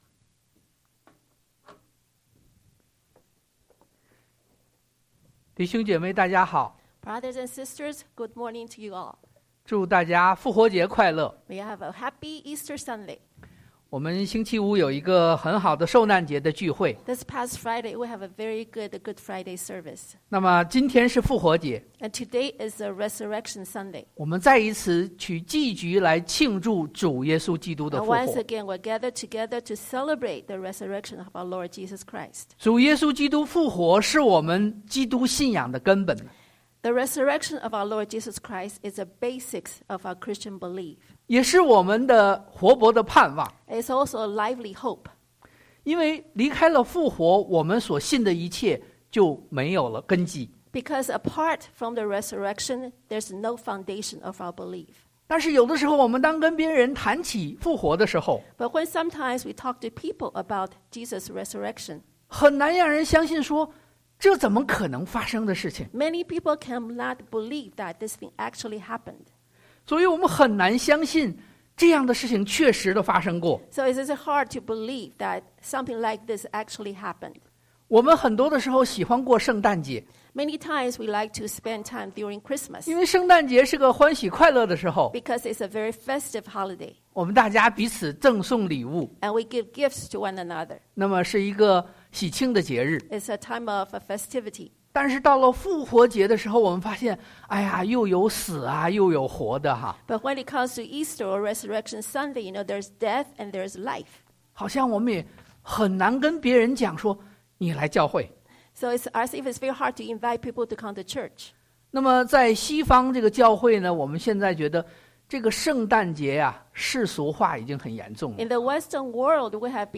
1Cor 15:1-6 Service Type: Sunday AM Bible Text